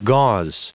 Prononciation du mot gauze en anglais (fichier audio)
Prononciation du mot : gauze